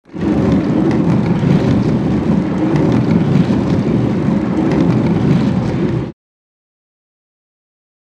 Scrape, Wood
Wood Scrape; Heavy Wood And Metal Object Drags Across Rough Surface With Rumble.